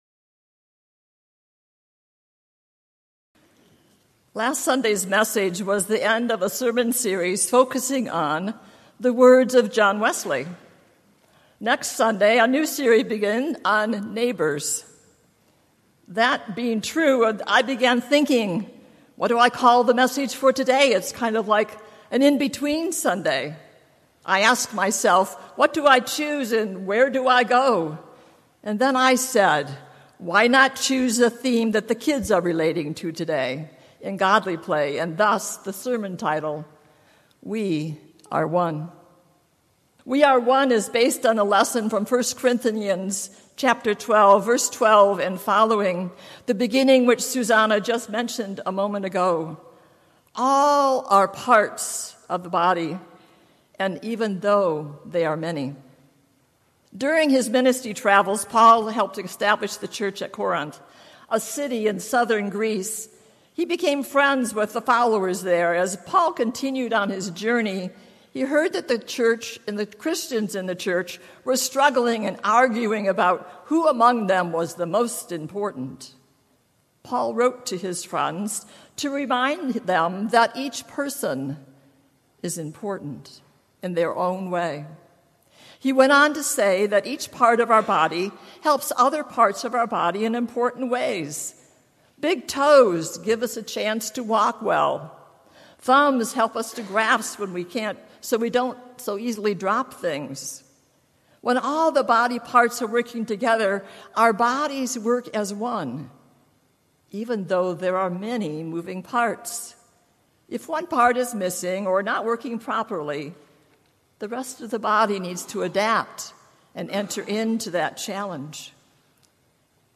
2019-09-01 Sermon, “We Are One”
Sept0119-Sermon.mp3